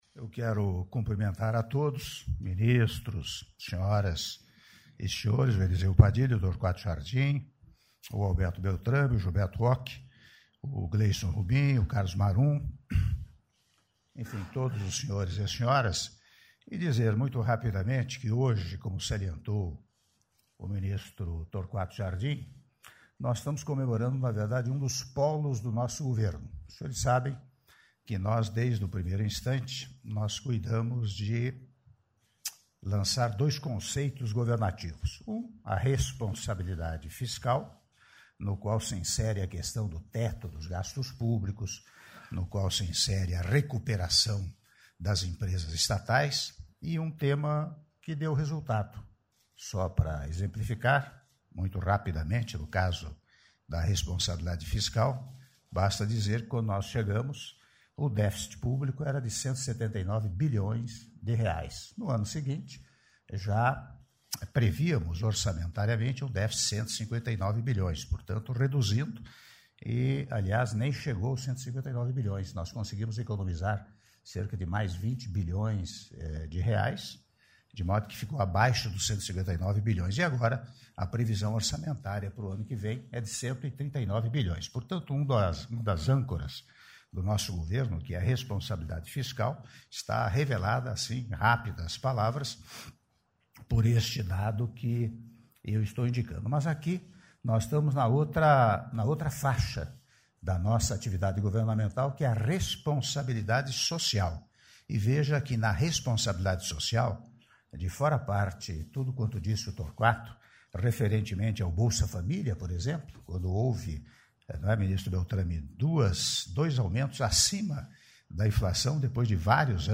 Áudio do discurso do Presidente da República, Michel Temer, durante a cerimônia de Anúncio de Vagas para Comunidades Terapêuticas de Acolhimento de dependentes Químicos -Brasília/DF- (06min26s)